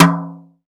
Brush Tom M1.wav